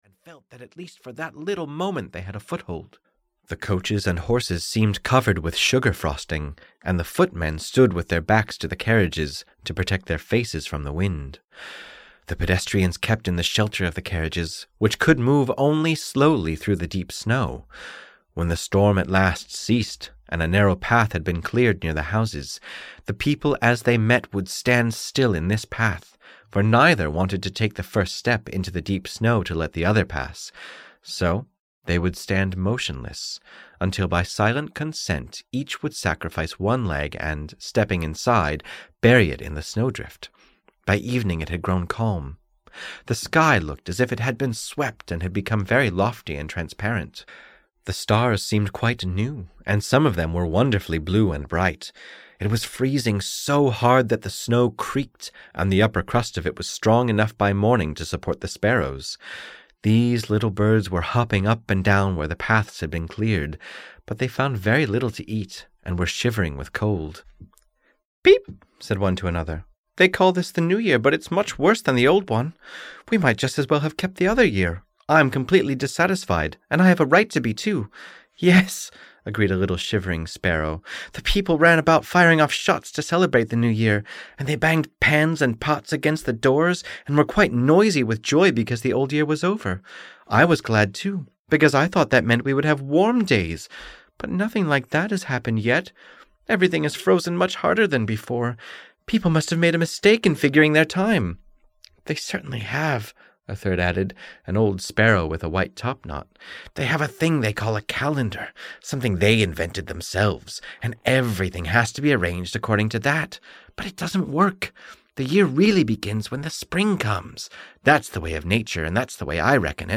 The Story of the Year (EN) audiokniha
Ukázka z knihy